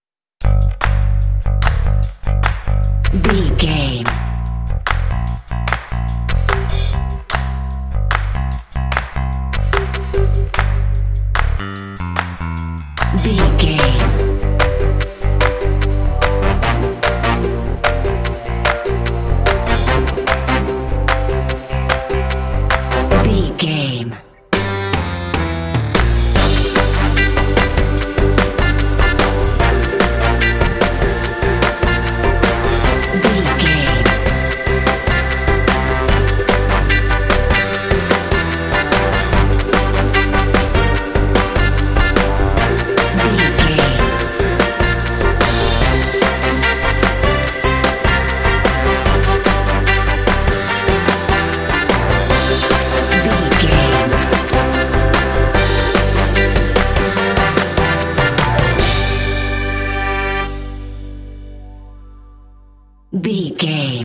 Aeolian/Minor
fun
bright
lively
sweet
brass
horns
electric organ
drums
bass guitar
modern jazz
pop